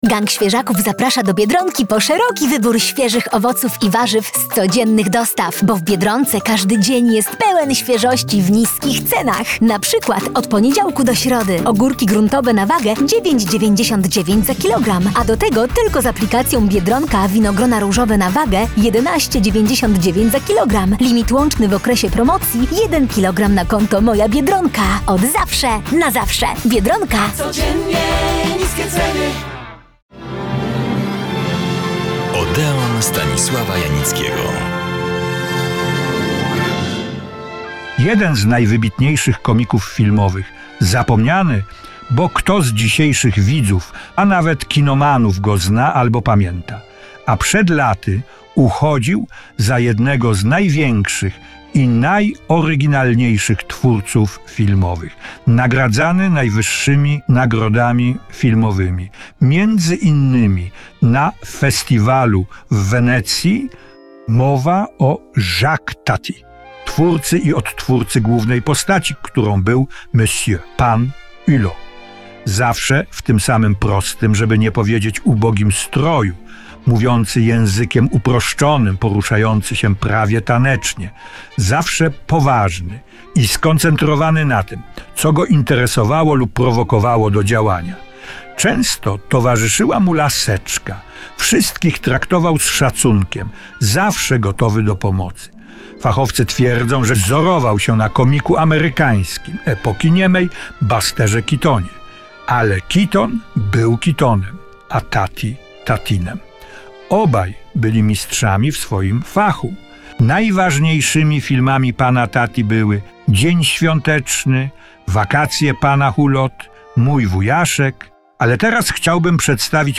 318. Świąteczny Nowy Jork: magia, tłumy i codzienność. Rozmowa z mieszkanką miasta - 02.12.2025